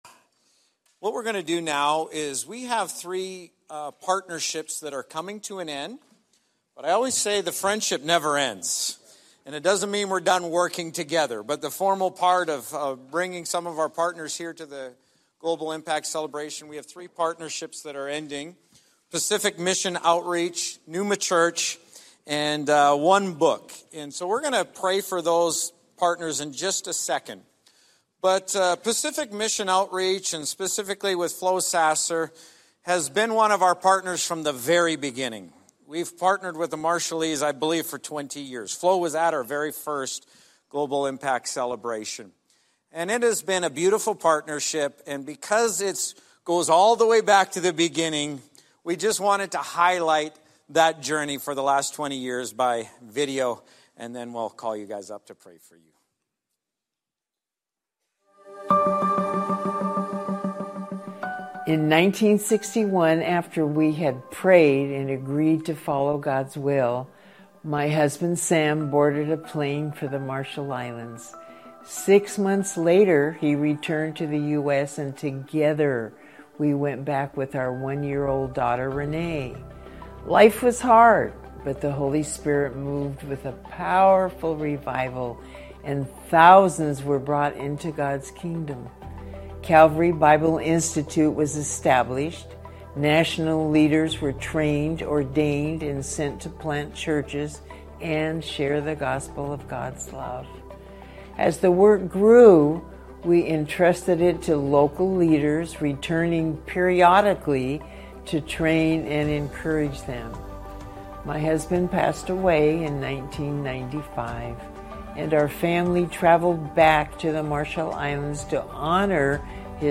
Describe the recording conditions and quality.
This message marks the conclusion of our 20th Annual Global Impact Celebration!